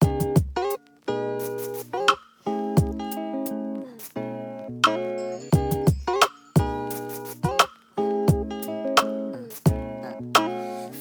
Vibe Out Sample.wav